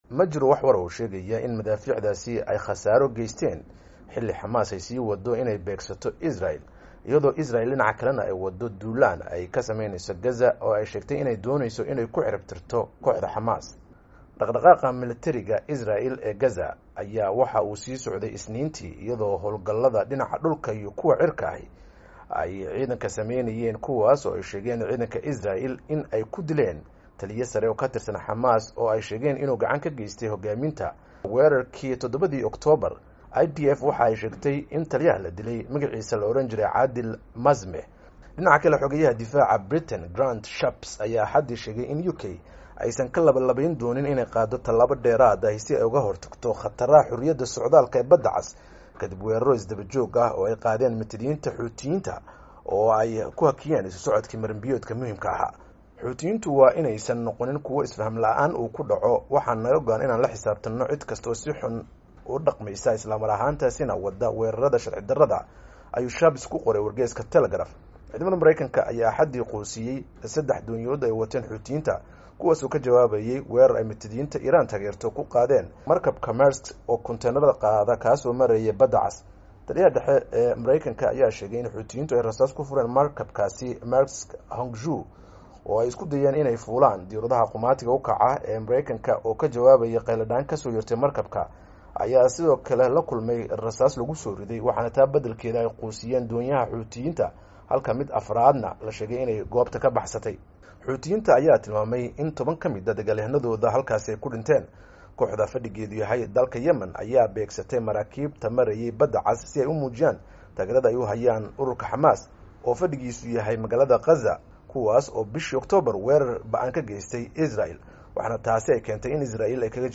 Warbixintan oo ay qortey VOA